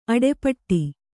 ♪ aḍepaṭṭi